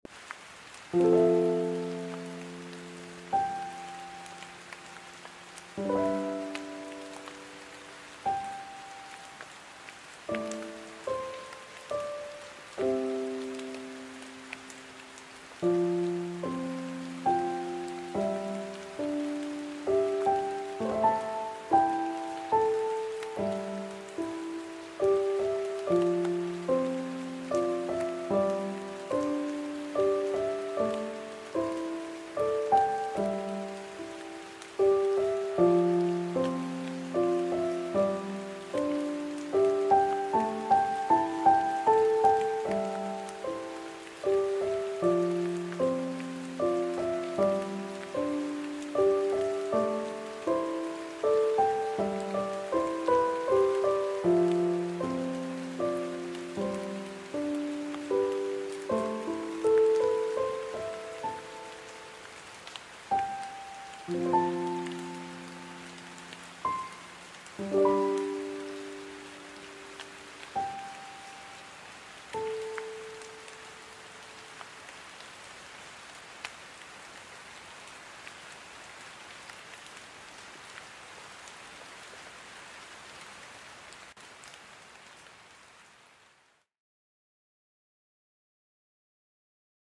【二人声劇】五月雨と竜胆